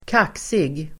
Ladda ner uttalet
Uttal: [k'ak:sig]